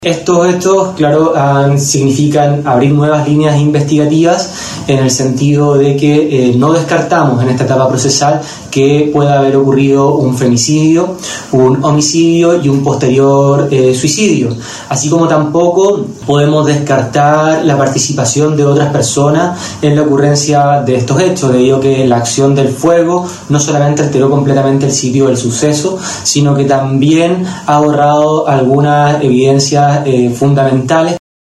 FISCAL-1.mp3